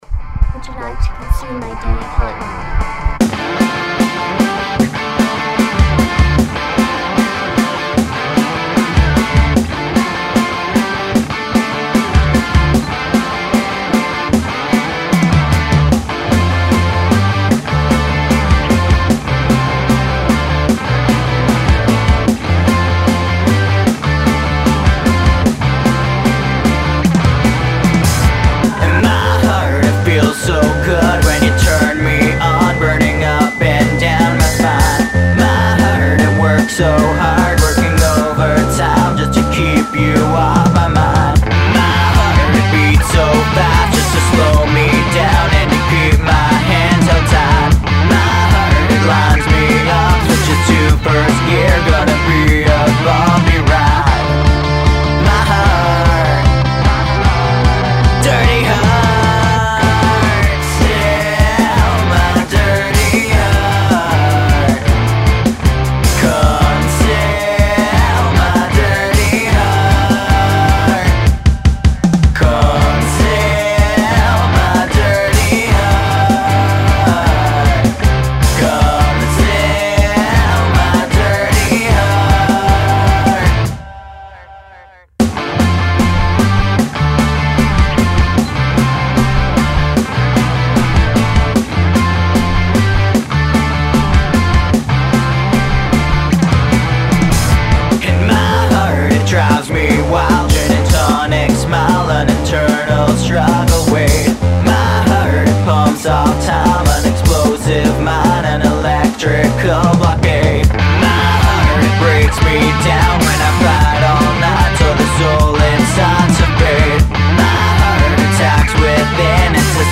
Hey everyone, I just recorded my first full song and mixed it. I'm not a singer but couldn't find anyone else so I sang for this song.